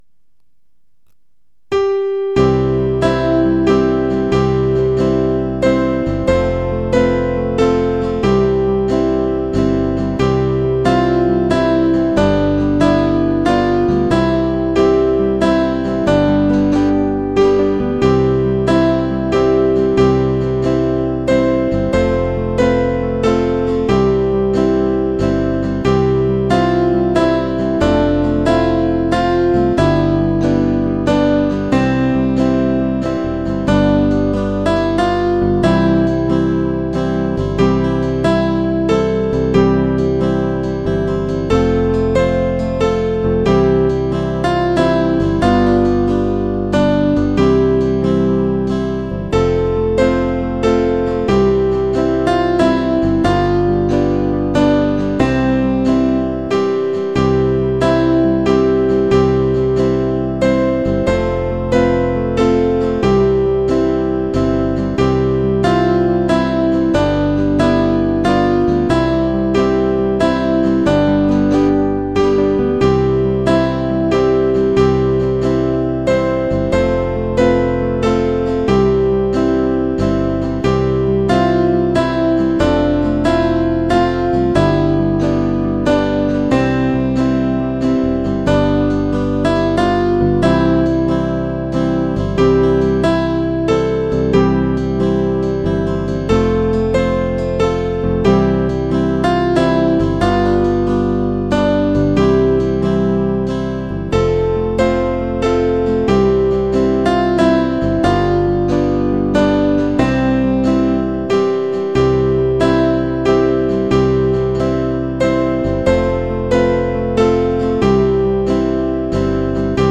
MP3 は楽譜の指示よりも速度が遅くなっています。
◆　４分の３拍子　：　３拍目から始まります。